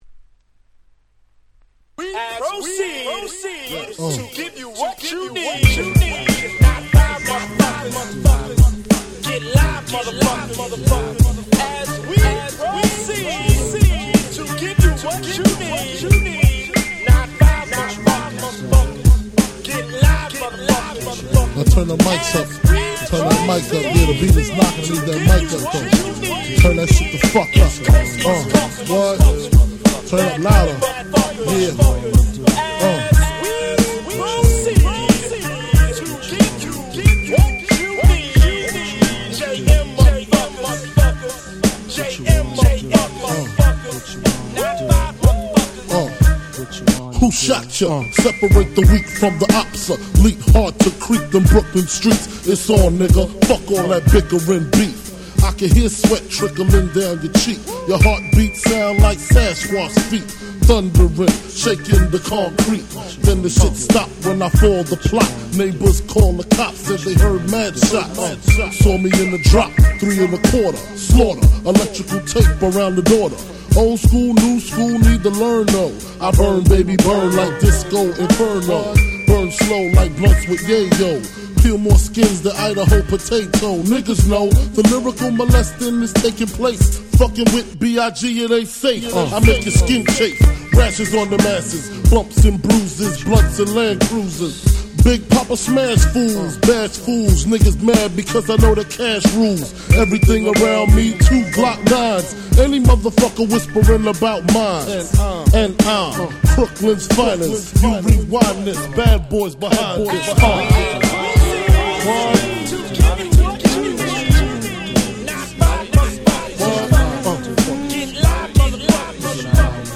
95' Super Hit Hip Hop !!
90's Boom Bap